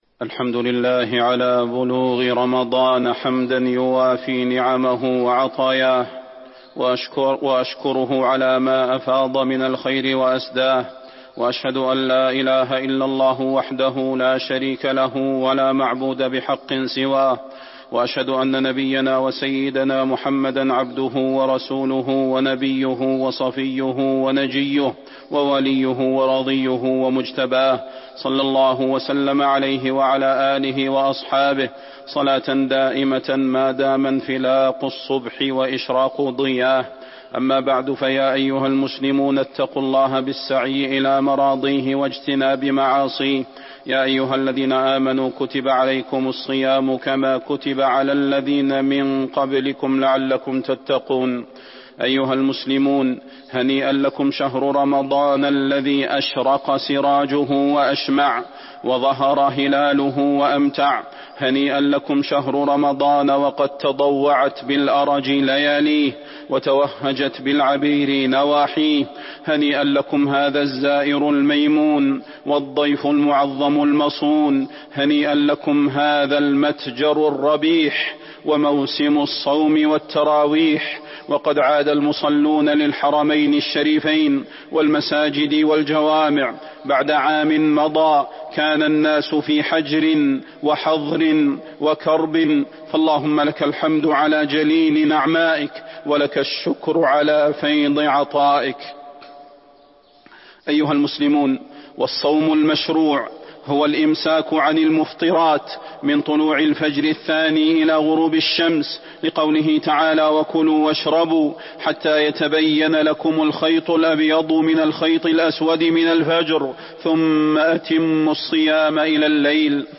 تاريخ النشر ٤ رمضان ١٤٤٢ هـ المكان: المسجد النبوي الشيخ: فضيلة الشيخ د. صلاح بن محمد البدير فضيلة الشيخ د. صلاح بن محمد البدير الصيام وأحكامه